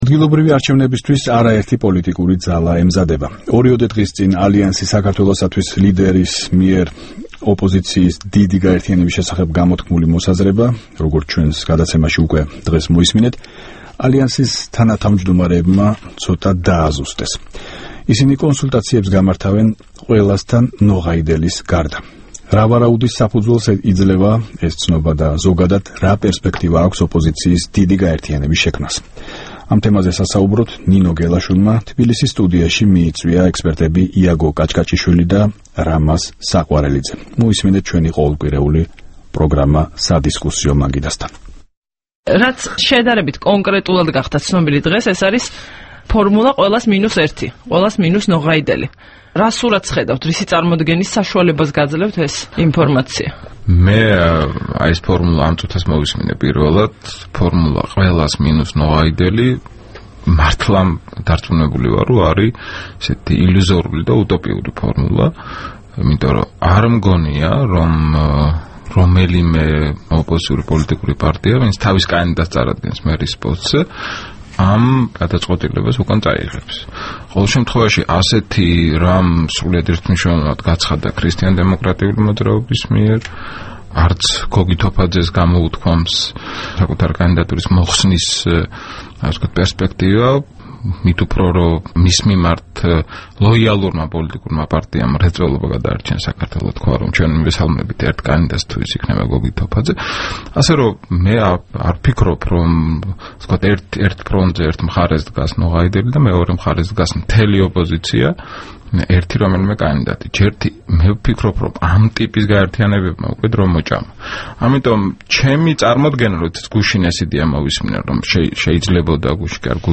დისკუსია